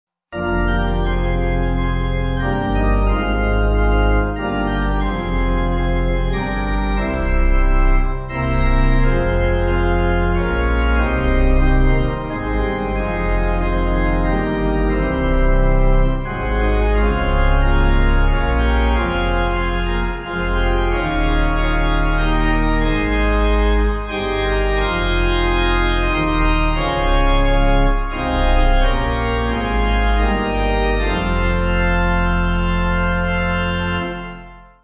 Organ
(CM)   4/Fm